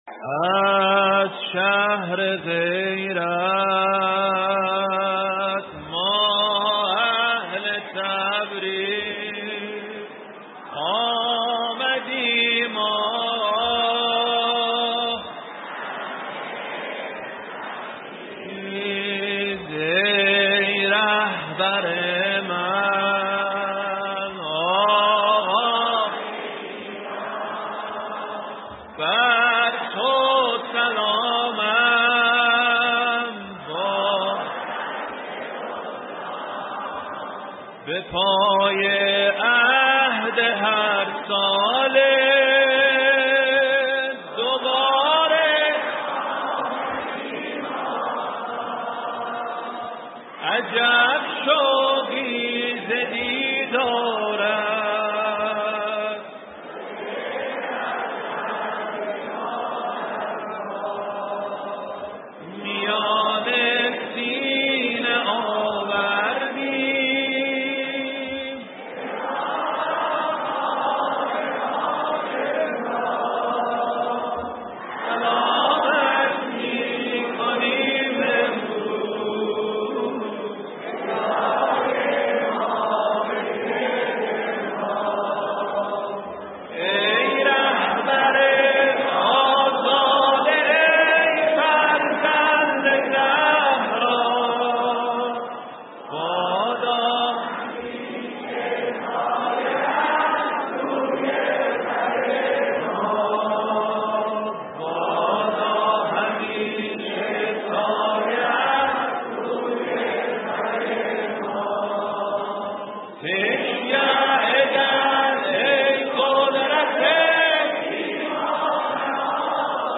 1 همخوانی سرود توسط مردم آذربایجان شرقی 7:16